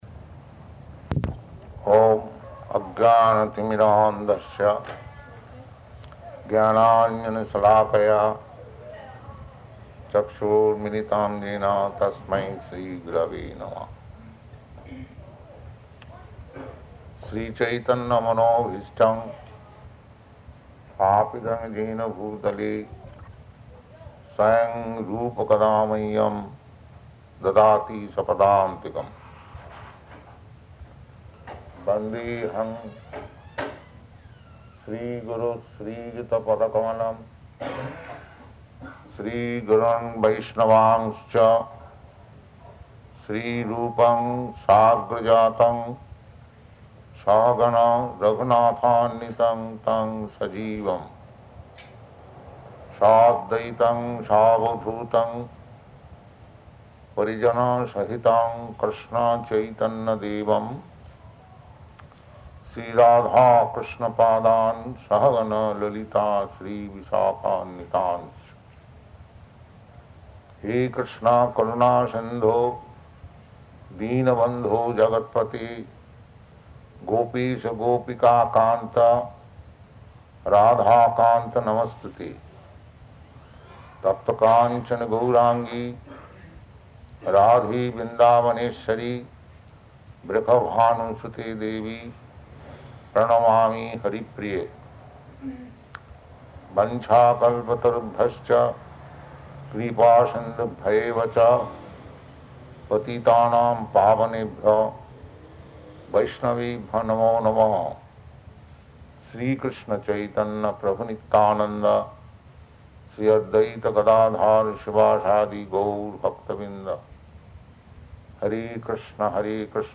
Location: Montreal
Prabhupāda: [chants maṅgalācaraṇa ]